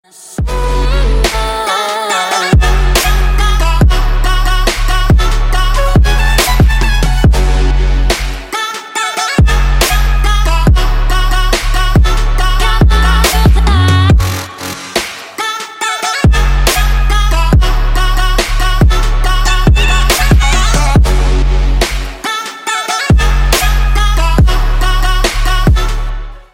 • Качество: Хорошее
🎶 Рингтоны